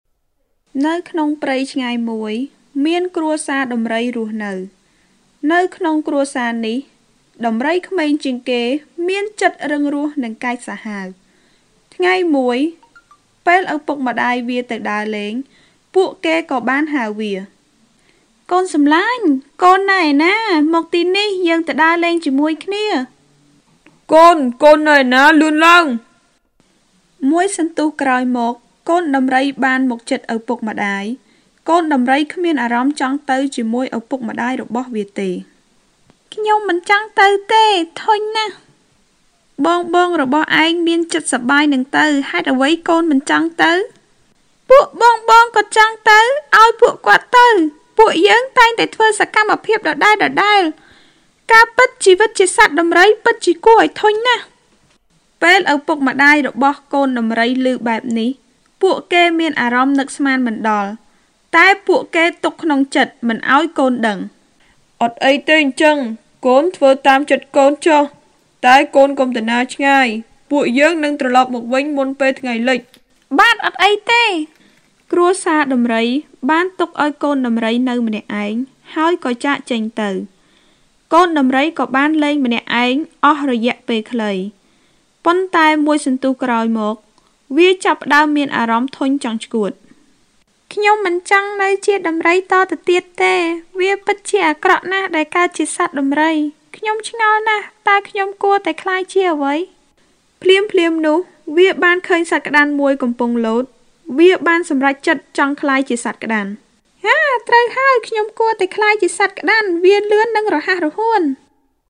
柬埔寨高棉语女声绘本故事 角色类 低沉|激情激昂|大气浑厚磁性|沉稳|娓娓道来|科技感|积极向上|时尚活力|神秘性感|调性走心|亲切甜美|感人煽情|素人